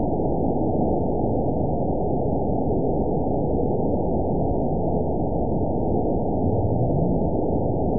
event 922734 date 03/24/25 time 05:28:59 GMT (2 months, 3 weeks ago) score 9.50 location TSS-AB10 detected by nrw target species NRW annotations +NRW Spectrogram: Frequency (kHz) vs. Time (s) audio not available .wav